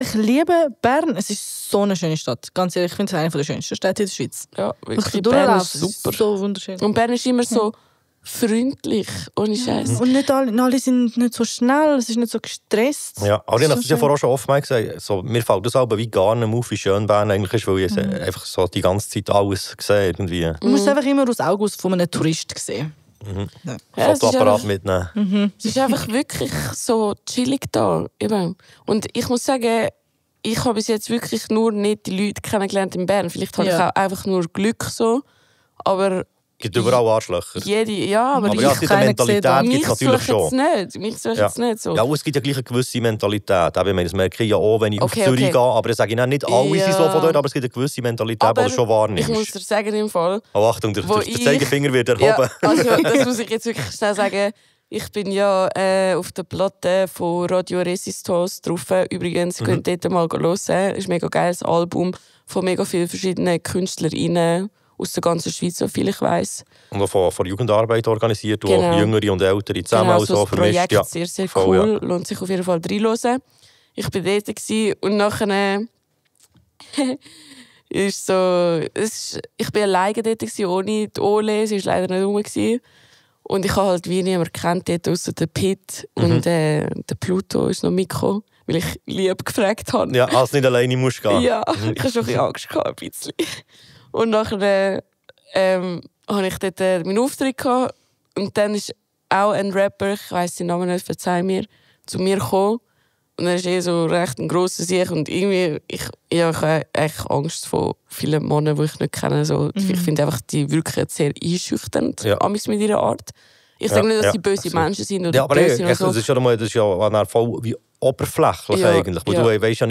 Aktuelle Beiträge aus Radio, Fernsehen und Zeitung mit Beteiligung des toj und teilweise mit Stimmen von Jugendlichen: